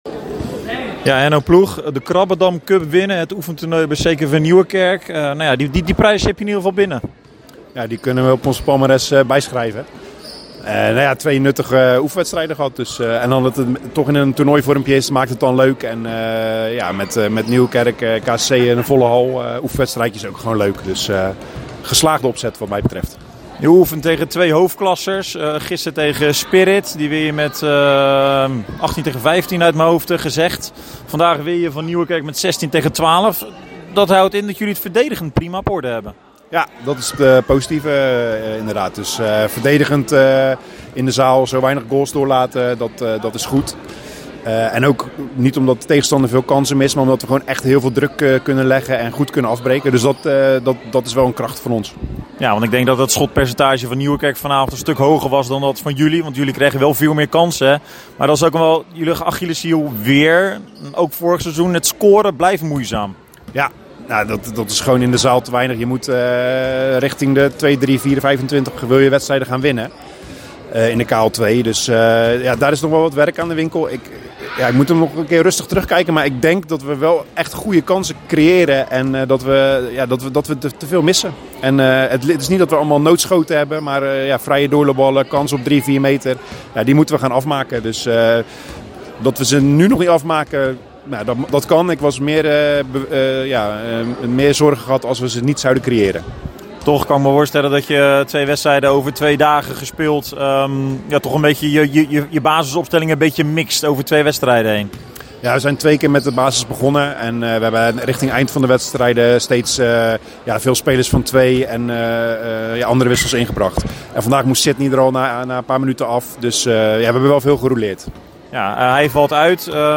na afloop sprak verslaggever